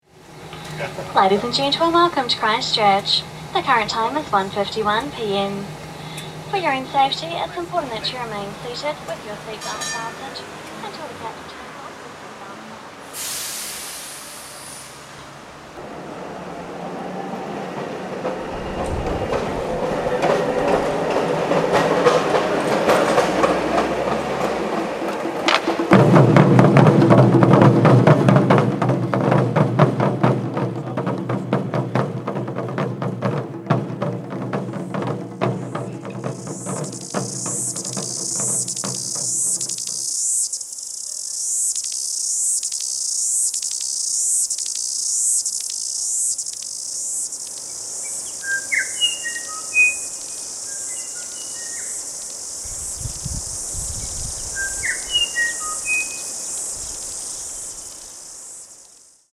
Here’s a recording to give you a sense of what it’s like to fly into New Zealand and emerge into the middle of summer. Listen closely, and you’ll hear a flight attendants’ greeting (in a pleasant New Zealand accent), a Christchurch tram heading into town, a group of Japanese taiko drummers giving an exhibition in the town square, a cicada with an incredible sense of rhythm, and the sweet, haphazard song of a bellbird, which is common in New Zealand but lives nowhere else in the world.
Sounds-of-summer-New-Zealand_1-2.mp3